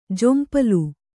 ♪ jompalu